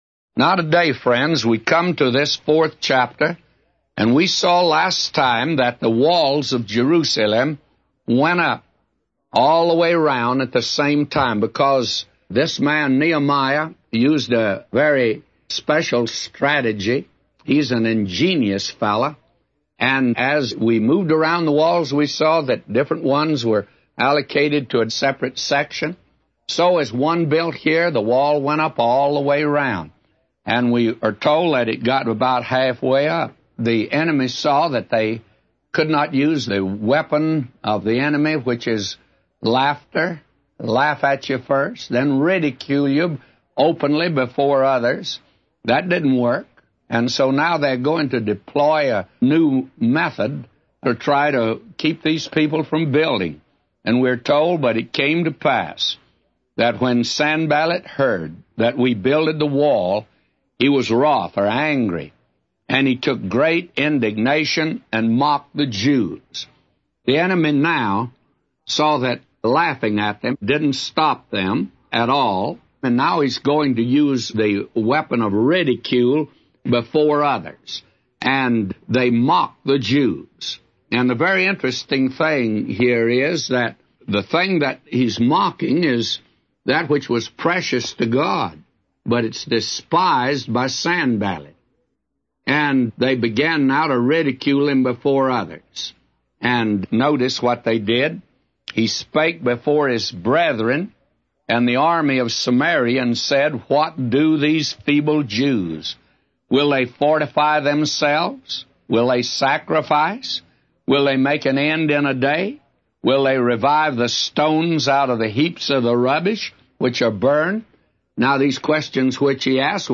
A Commentary By J Vernon MCgee For Nehemiah 4:1-999